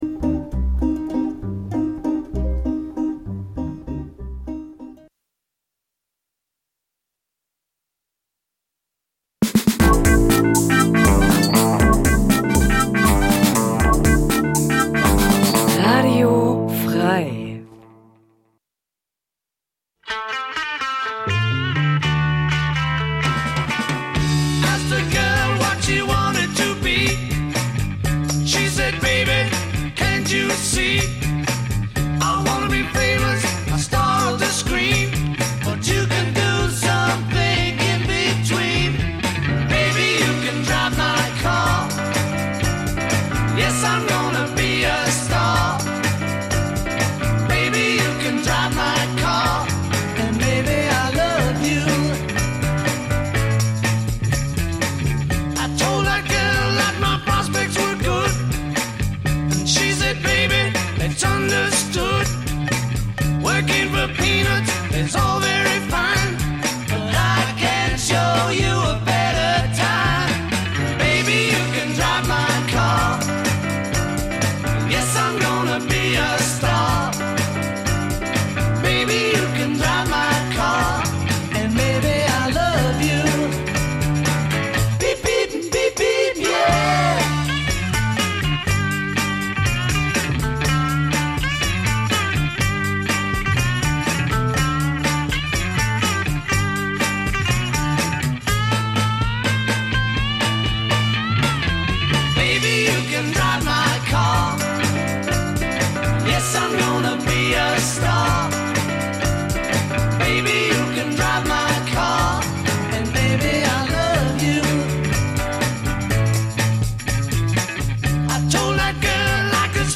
Wenn der Groove Deine Seele streichelt, die Drums ungezogen ins Ohr fl�stern, w�hrend Loops und Schleifen in entspannten Kreisen schweifen und dabei mehr gelacht als gedacht wird, sind Deine Ohren bei Radio Bounce - Gurgelnd knusprige Wellen aus der Hammerschmiede f�r leidenschafltiche Sch�ngeister, pudelnackt!